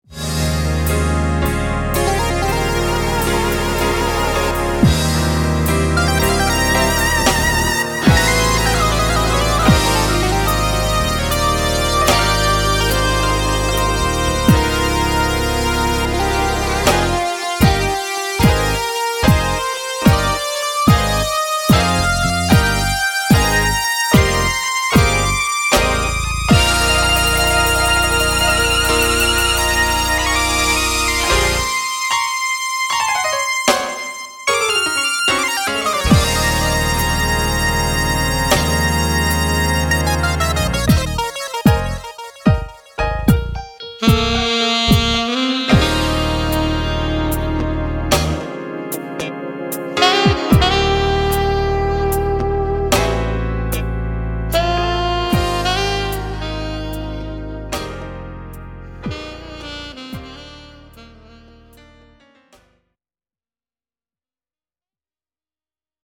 Genre: Gospel.
Gospel jazz